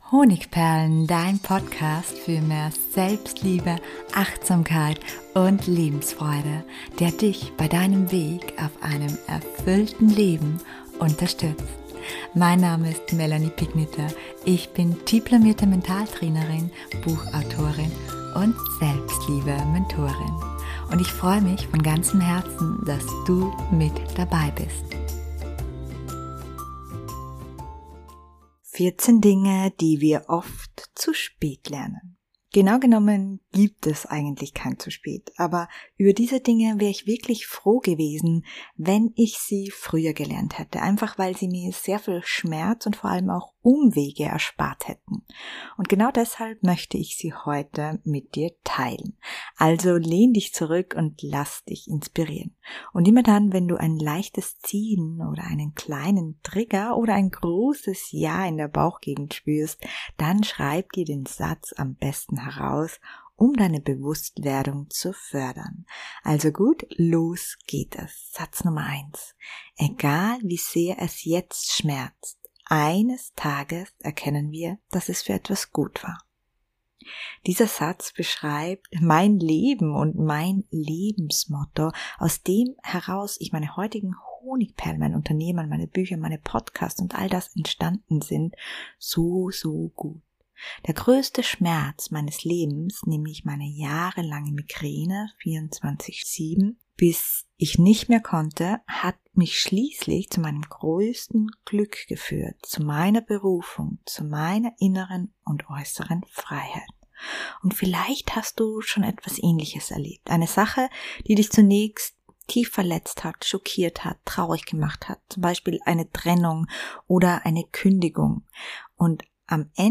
Eine integrierte Phantasiereise und tiefenwirksame Affirmationen helfen dabei.